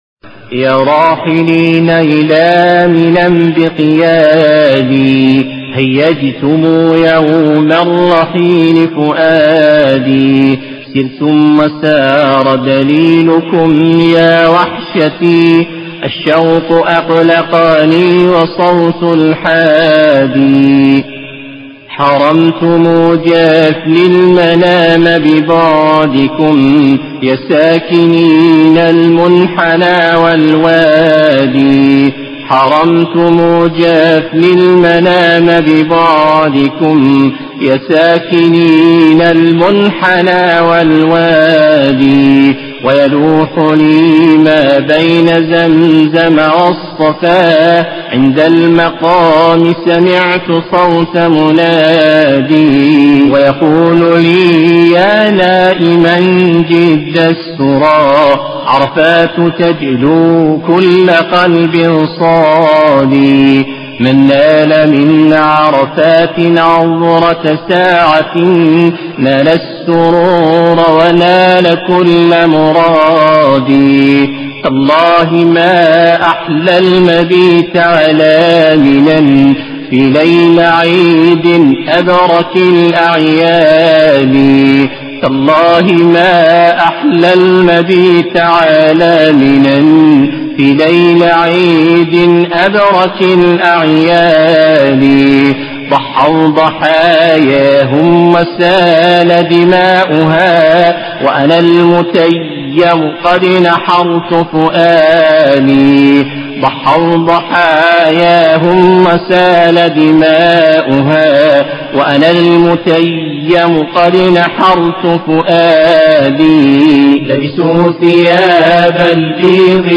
انشادا